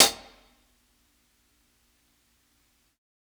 60s_HH_SOFT .wav